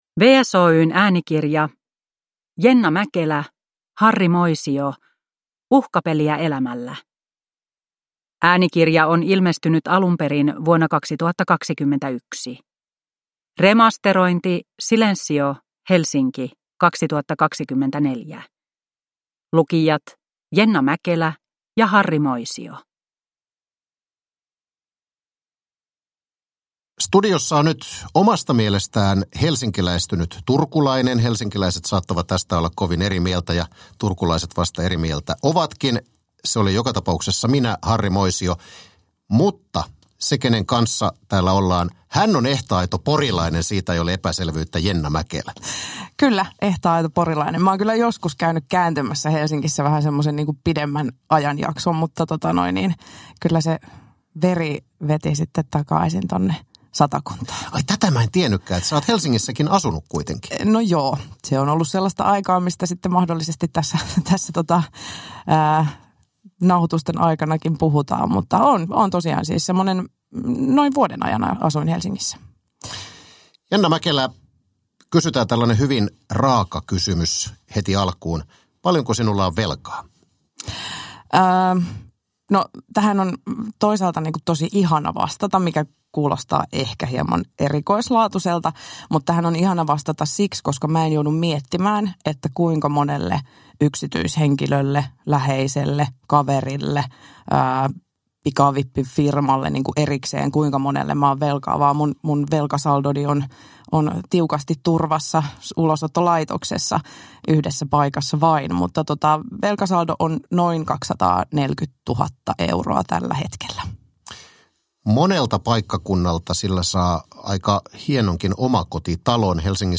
Uhkapeliä elämällä – Ljudbok